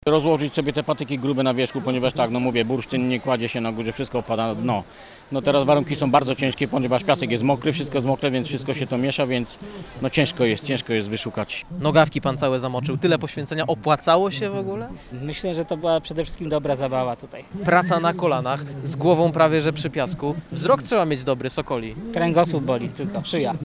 Poszukiwania trwają w pełnym skupieniu, a o zwycięską taktykę pytał uczestników nasz reporter.